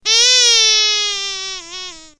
clock06.ogg